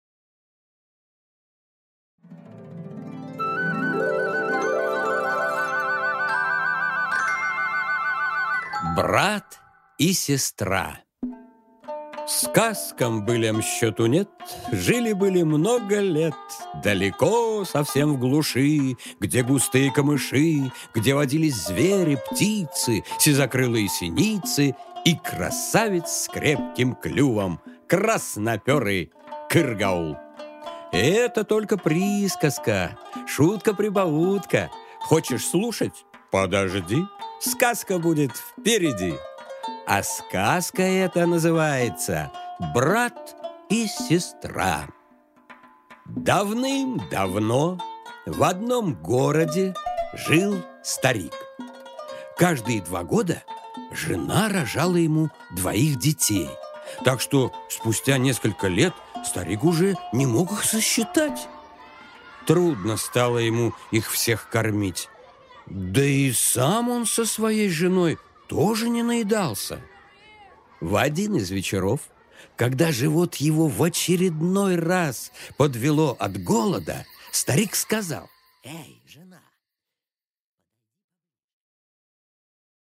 Аудиокнига Брат и сестра | Библиотека аудиокниг